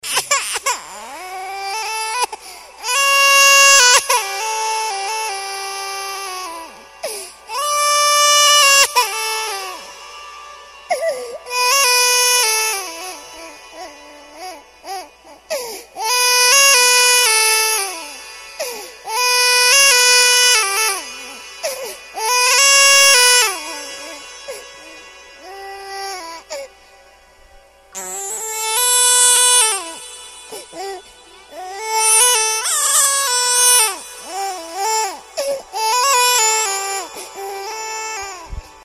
Скачать. Оповещение СМС. Плач ребенка. mp3 звук
Хотите скачать без SMS короткий mp3 фрагмент "Плач ребенка"?